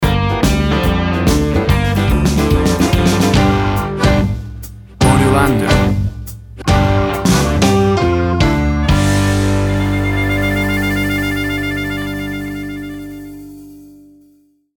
Tempo (BPM) 144